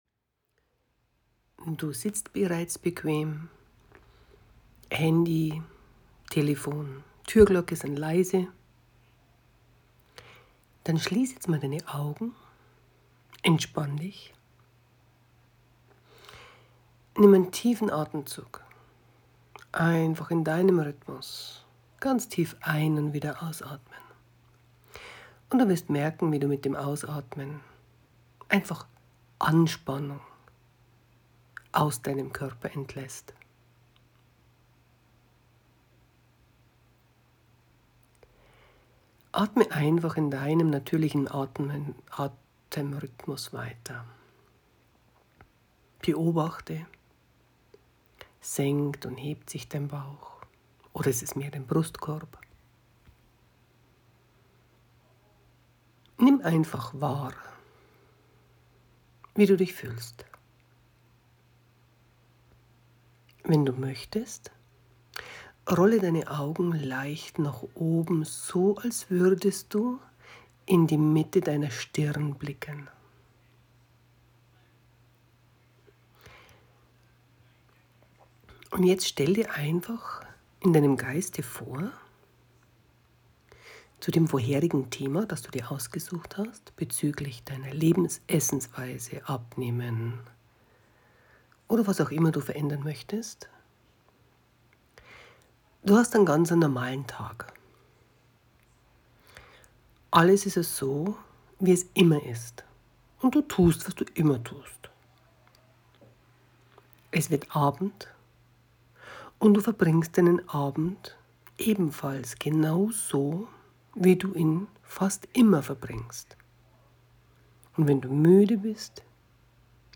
Kleine gesprochene Unterstützung für dich - Wunderfrage.mp3